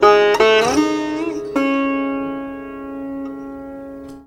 SITAR LINE29.wav